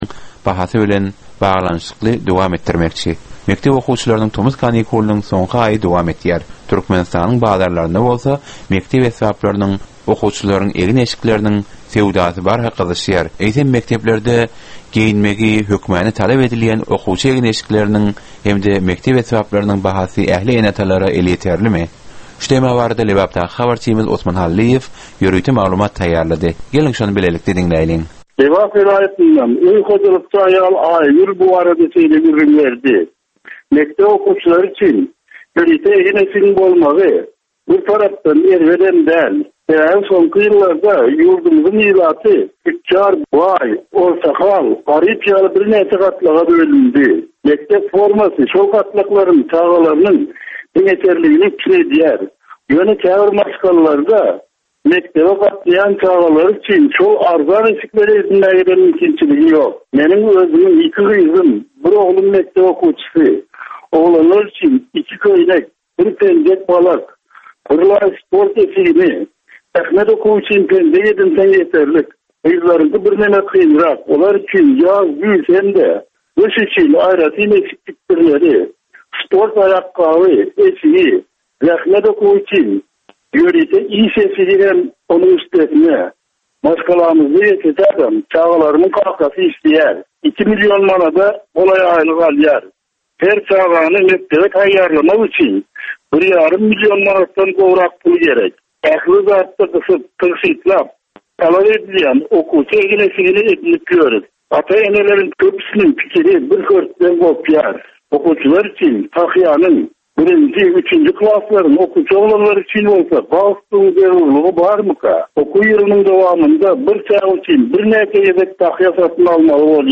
Türkmen we halkara yaslarynyn durmusyna degisli derwaýys meselelere we täzeliklere bagyslanylyp taýýarlanylýan 15 minutlyk ýörite geplesik.
Geplesigin dowmynda aýdym-sazlar hem esitdirilýär.